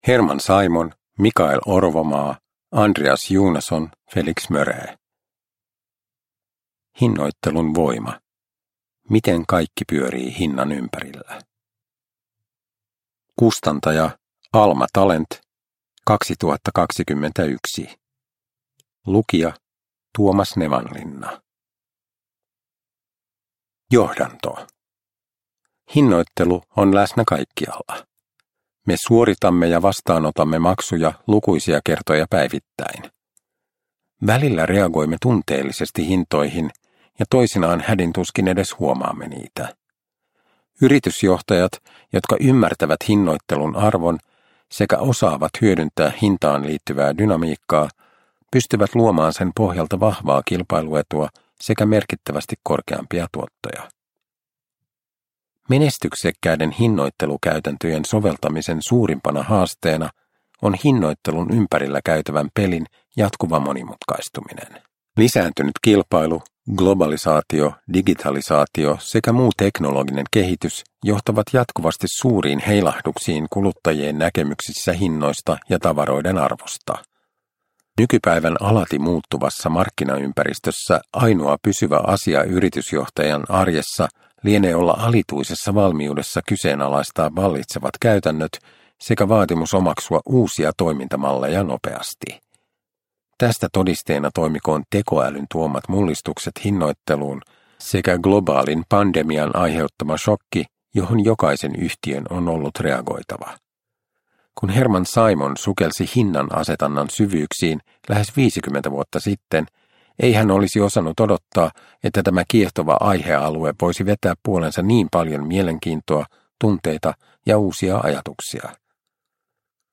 Hinnoittelun voima – Ljudbok – Laddas ner